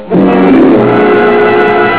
dramatic chord.)